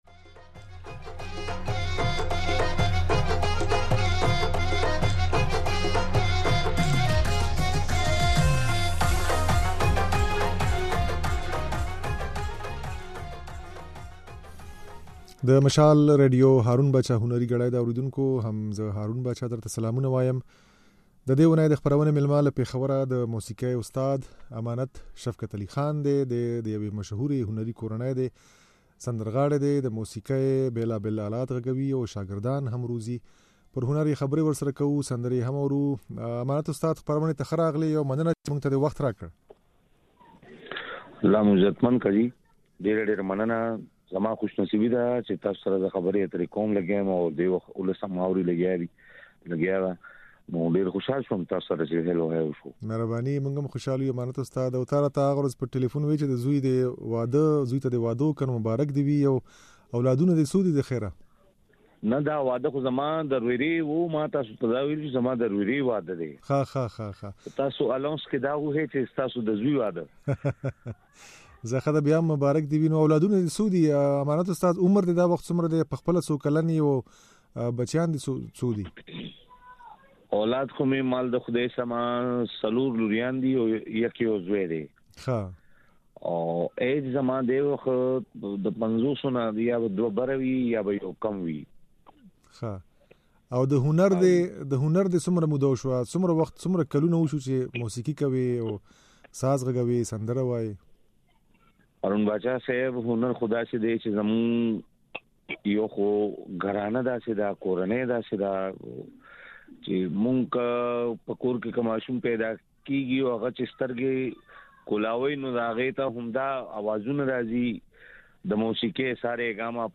د نوموړي د ژوند او هنر په اړه بشپړه خپرونه واورئ چې پکې نغمې هم لرو.